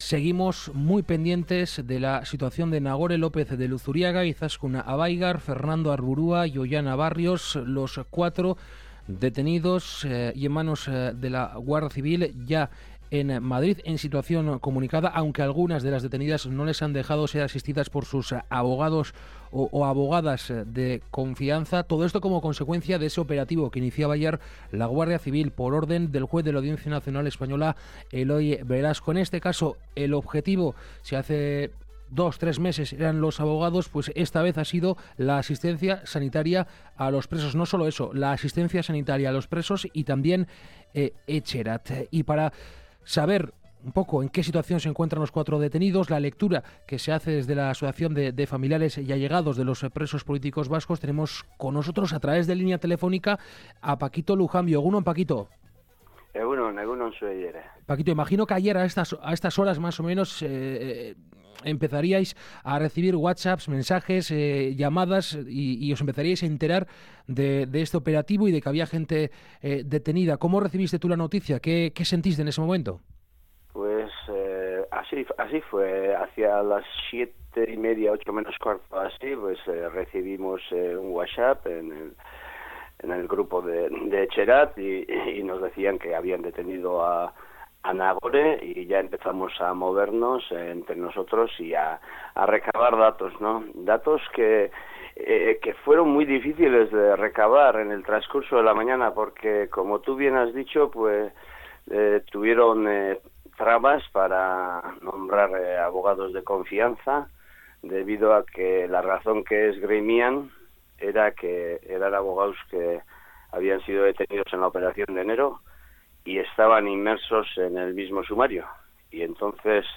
En este contexto hemos entrevistado a primera hora de la mañana